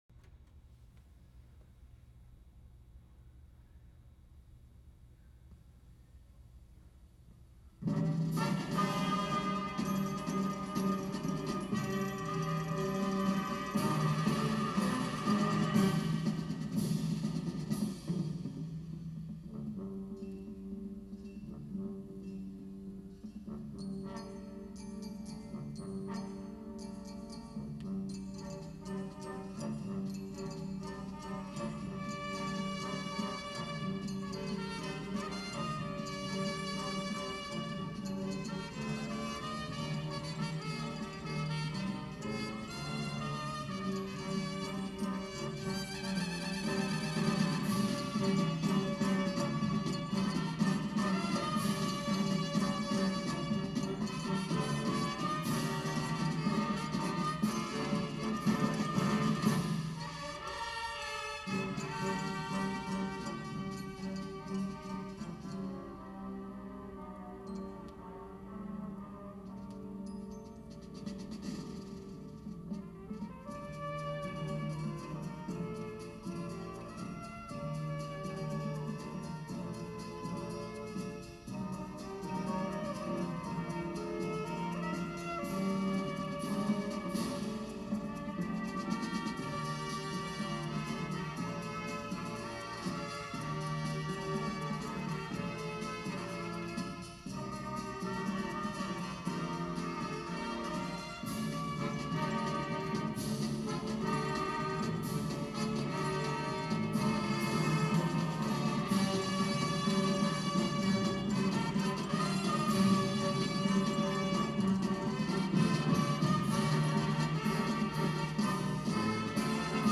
The Marching Band was awarded 1st Place - Division III Parade Band at the Buckwheat Festival's "School Days Parade" in Kingwood, WV.